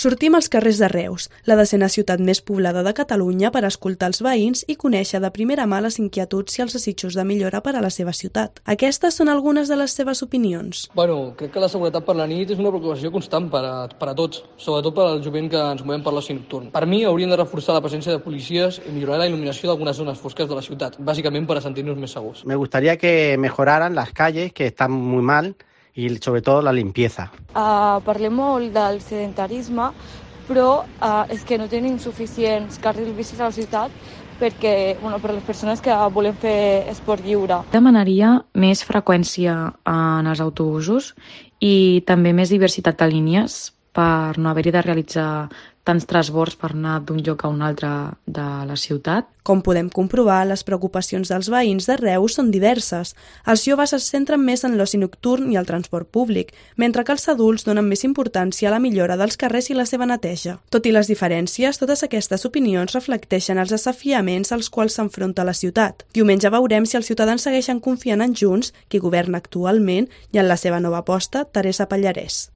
Qué opinan los vecinos de Reus de las elecciones del próximo 28 de mayo
Preguntamos a los vecinos de Reus qué esperan de las elecciones del 28 de mayo y qué cosas querrían que cambiasen.